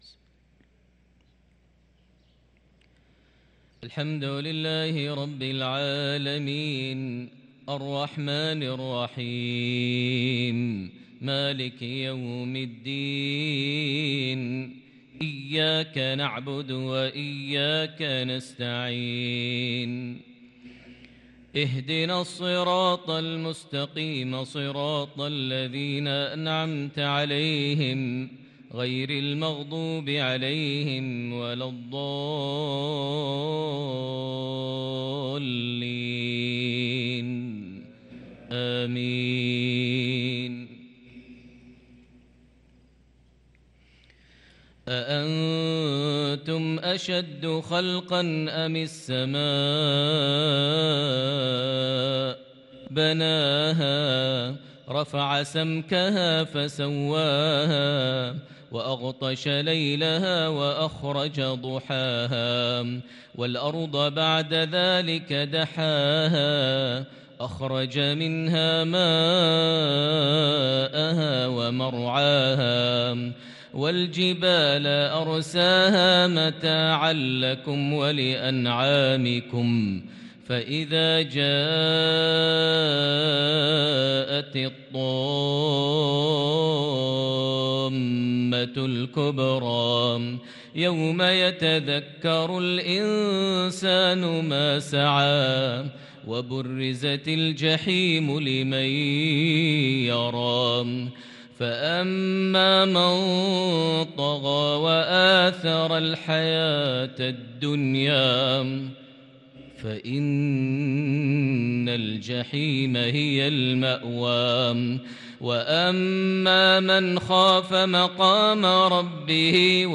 صلاة المغرب للقارئ ماهر المعيقلي 21 شعبان 1443 هـ
تِلَاوَات الْحَرَمَيْن .